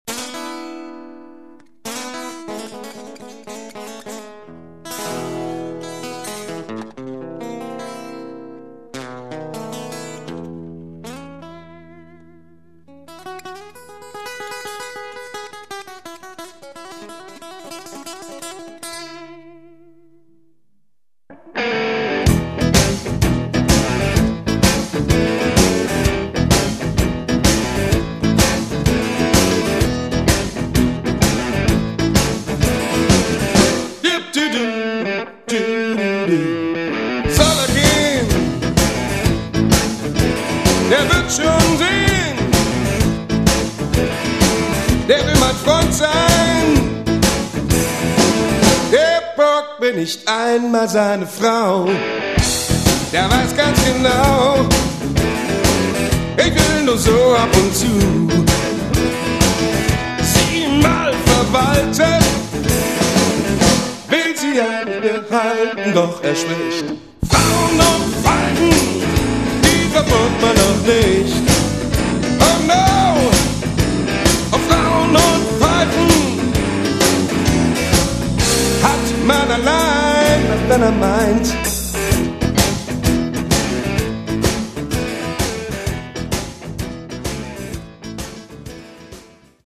Git.und Voc.
Bass und Chor
Dr. und Chor